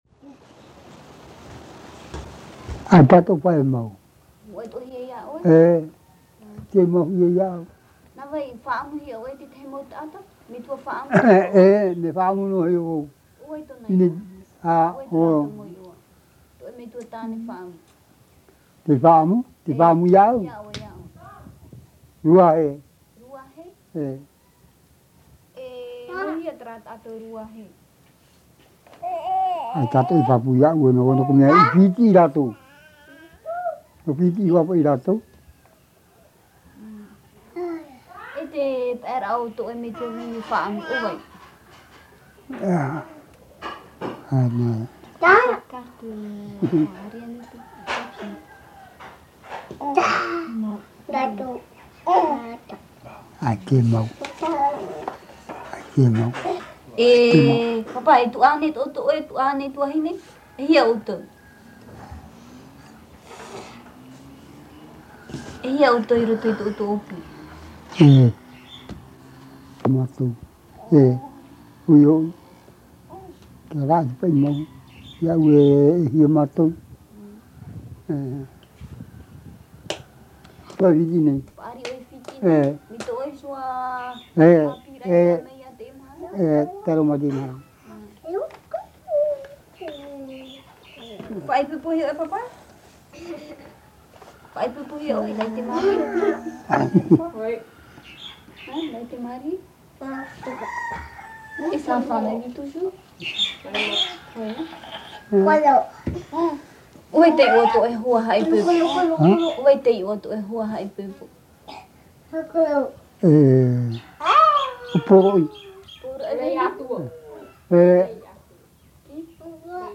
Descriptif de l'interview
Papa mātāmua / Support original : Cassette audio